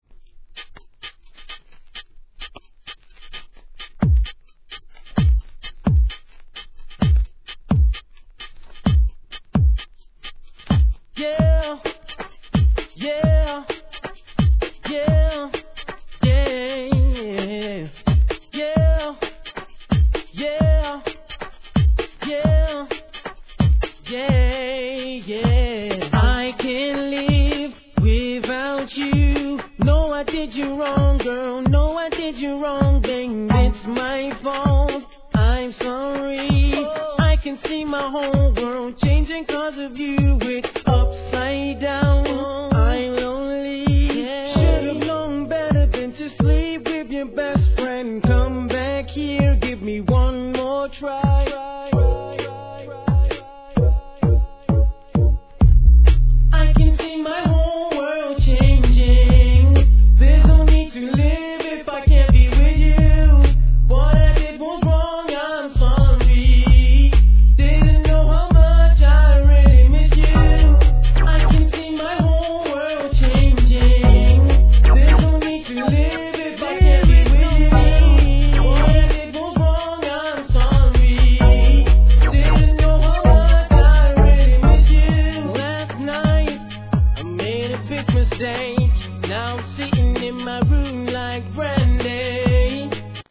HIP HOP/R&B
B/WにはDUB収録で、デジタルなDUB処理が好きな方にはツボ!